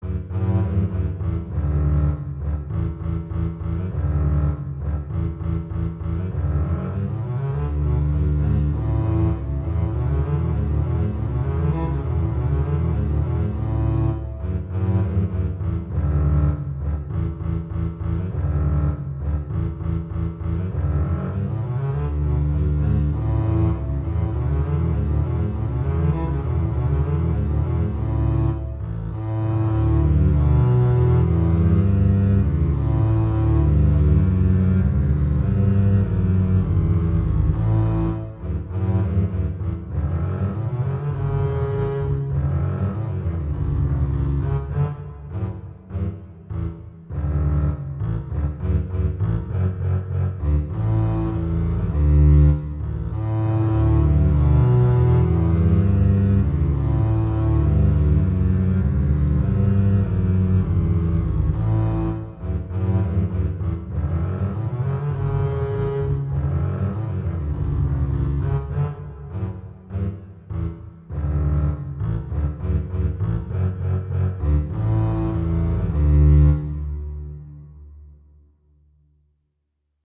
Instrumentation: two double-basses
transcription for two double-basses
classical, children
G major, D major, C major, Eb major
♩=88-120 BPM